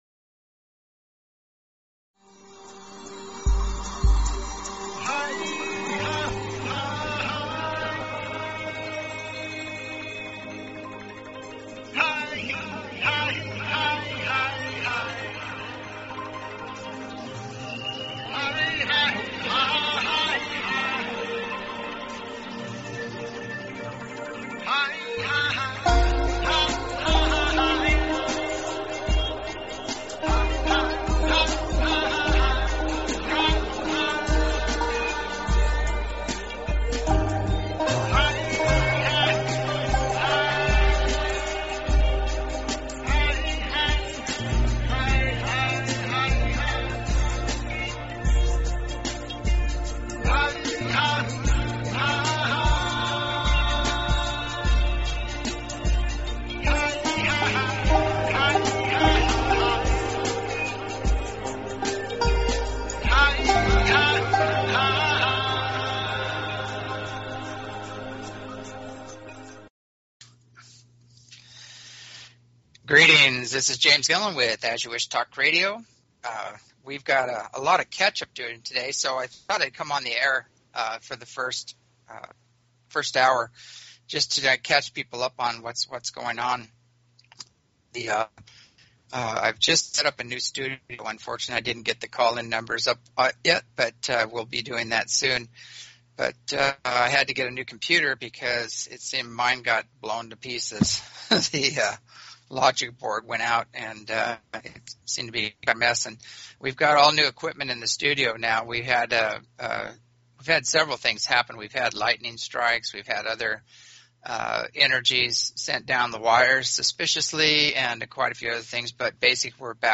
Talk Show Episode, Audio Podcast, As_You_Wish_Talk_Radio and Courtesy of BBS Radio on , show guests , about , categorized as
As you Wish Talk Radio, cutting edge authors, healers & scientists broadcasted Live from the ECETI ranch, an internationally known UFO & Paranormal hot spot.